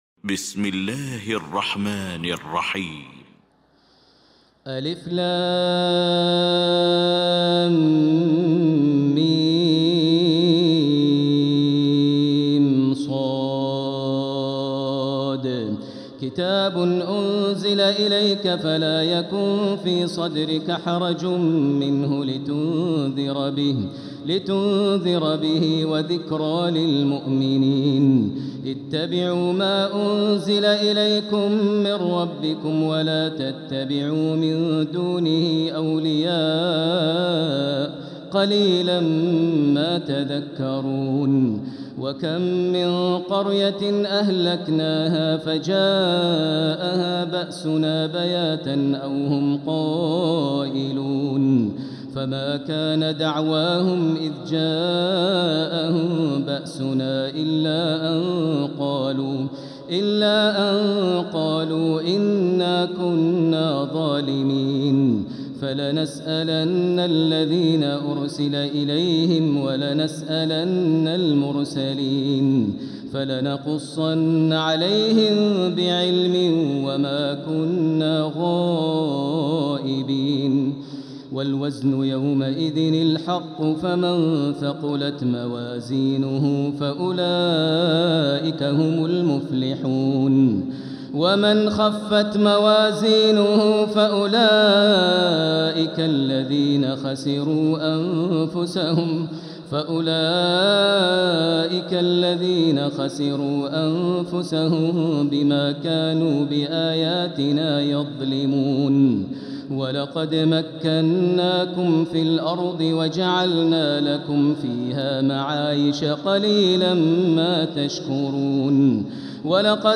المكان: المسجد الحرام الشيخ: بدر التركي بدر التركي معالي الشيخ أ.د. بندر بليلة فضيلة الشيخ ماهر المعيقلي فضيلة الشيخ ياسر الدوسري الأعراف